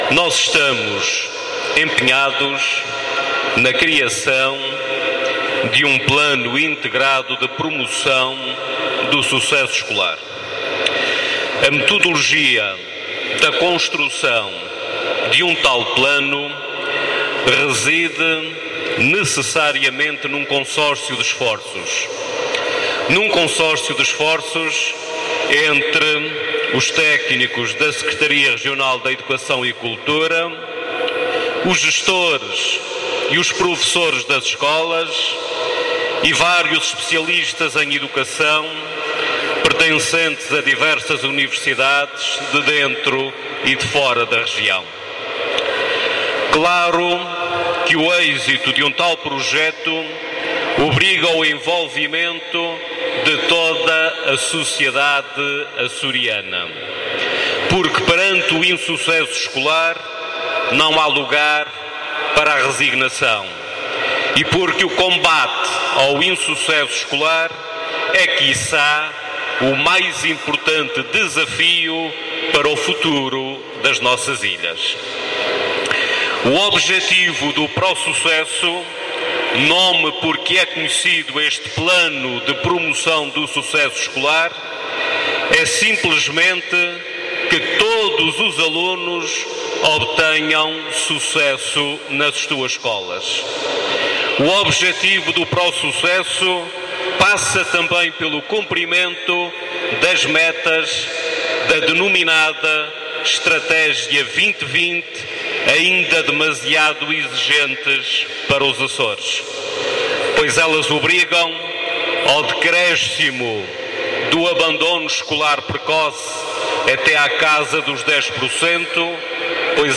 Avelino Meneses, que falava na abertura dos XXVI Jogos Desportivos Escolares, salientou que, no contexto do Plano Integrado de Promoção do Sucesso Escolar, denominado ProSucesso, o desporto escolar “possui uma importância extraordinária”.